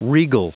Prononciation du mot regal en anglais (fichier audio)
Prononciation du mot : regal